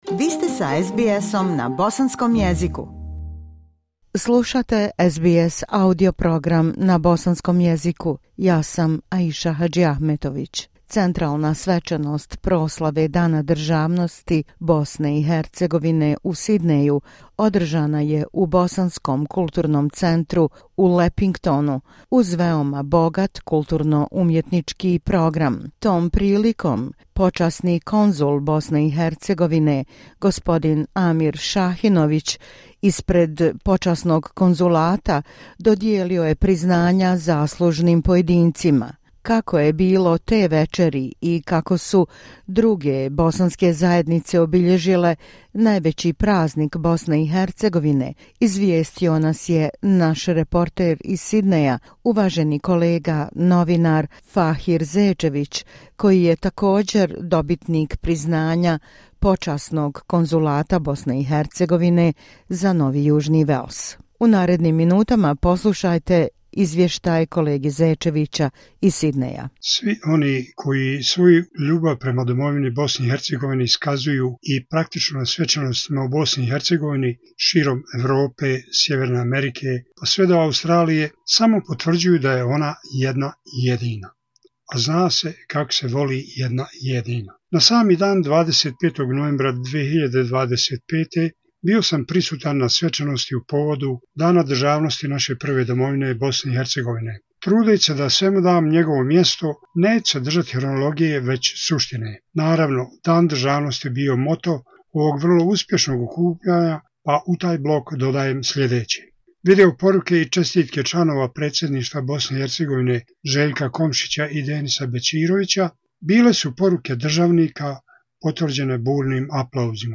SBS na bosanskom jeziku